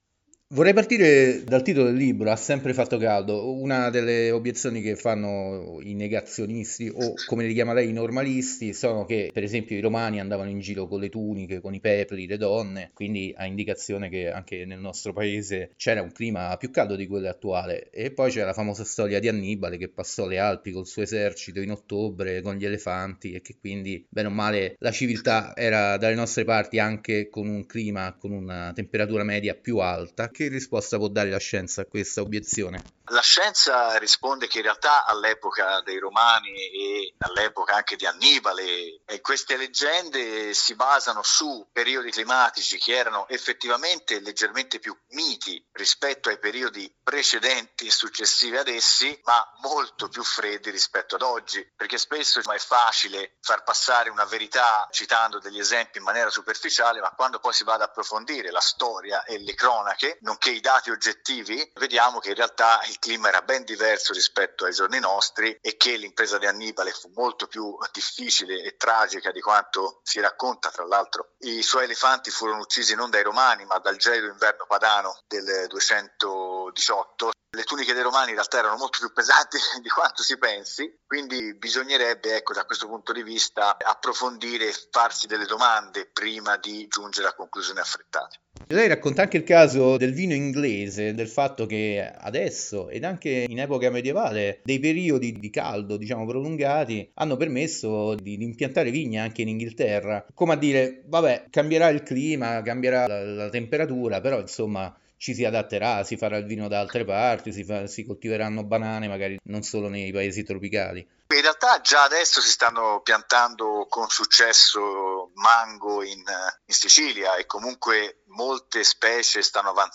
Cambiamento Climatico Interviste